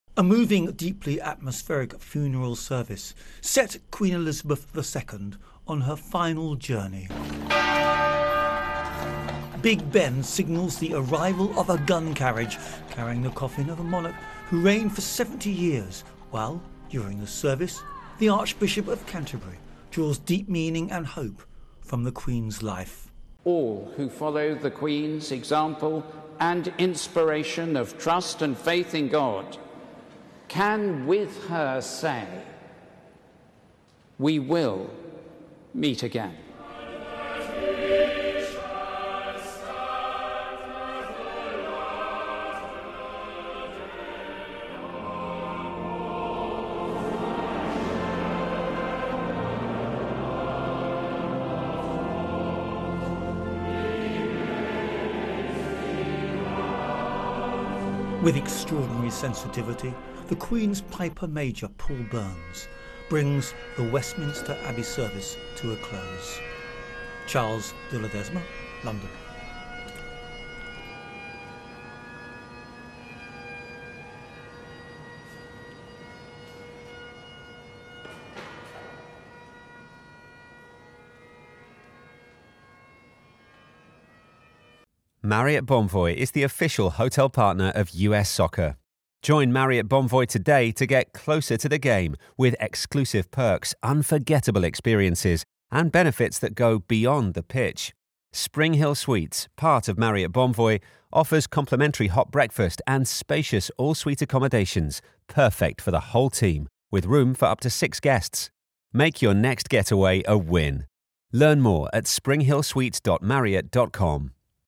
Sound Collage. ((With 15" fade out))